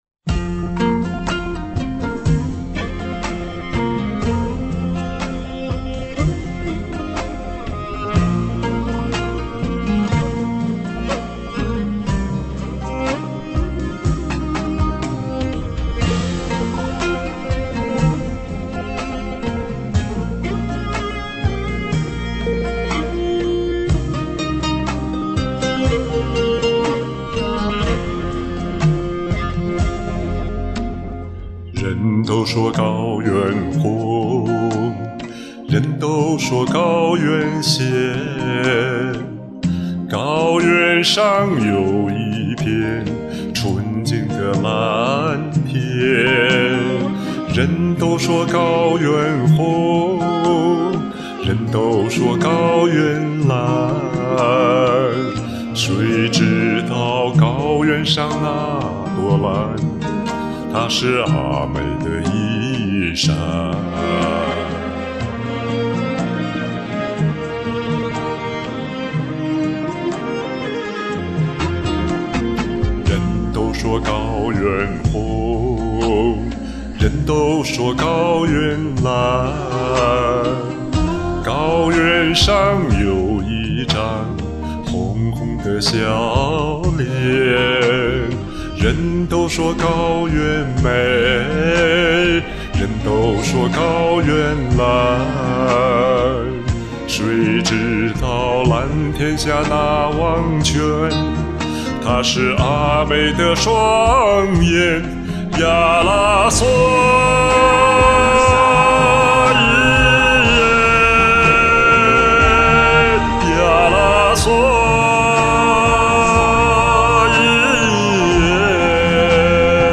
这是低音炮扛上了高原上！
第一次听男版，副歌部分很出彩